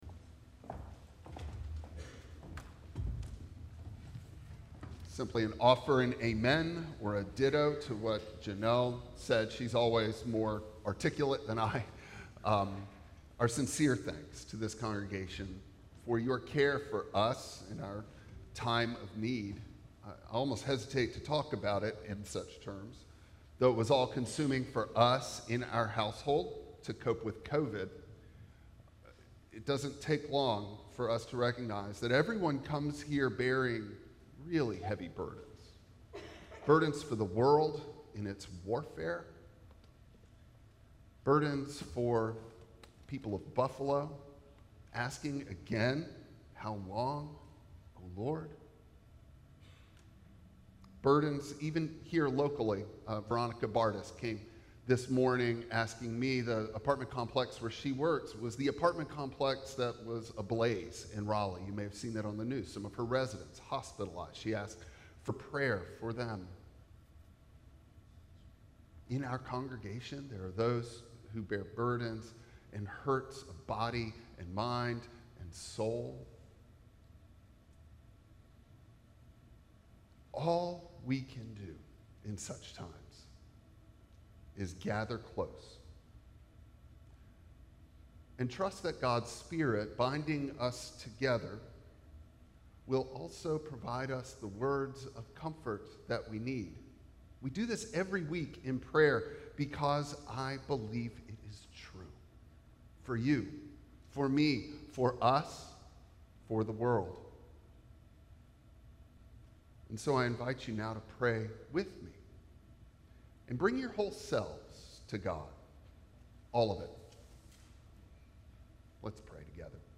Passage: John 21:15-19 Service Type: Traditional Service Bible Text